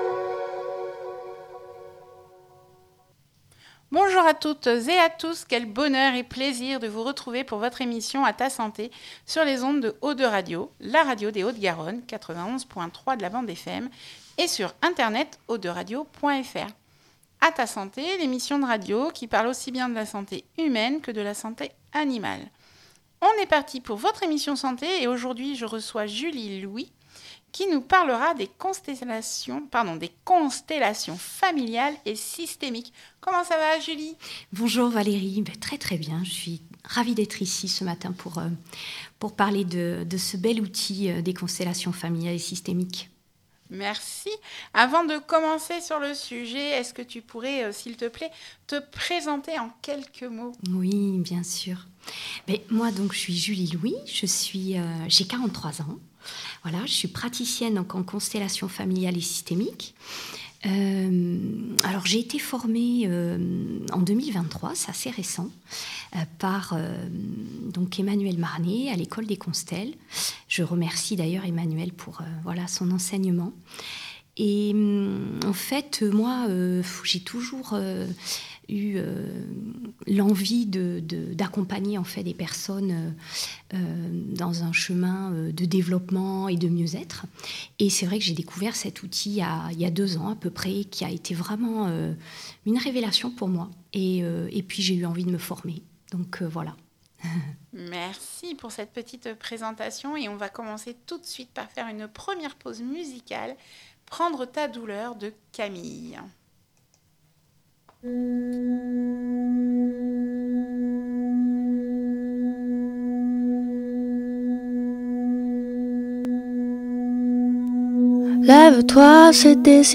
Ma conférence Radio